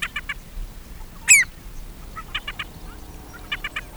gull.wav